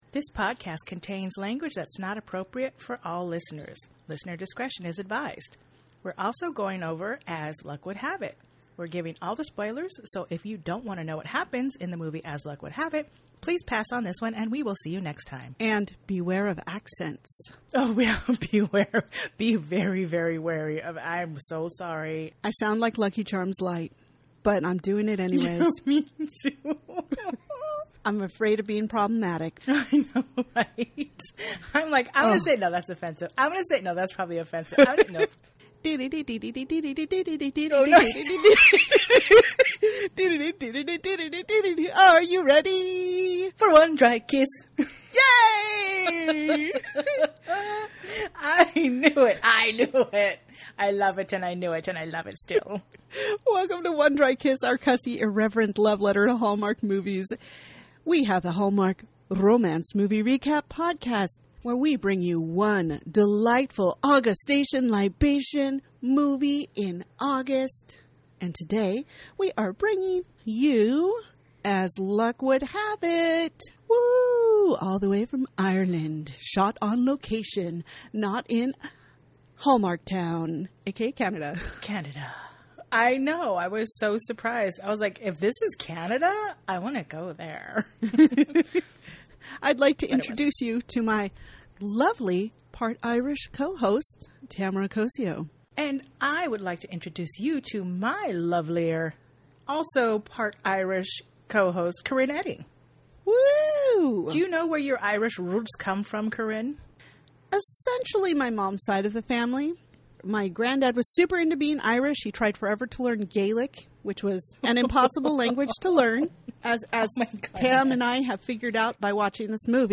In this episode we cover alleged goat shenanigans, overuse our favorite Irish catchall phrase and discuss what we think about speed dating (a little bit yes and a little bit no). We also channel our inner Lucky Charms Leprechaun with a bunch of bad accents and hope you forgive us because As Luck Would Have It, we have the best listeners and that's also the name of this week's movie.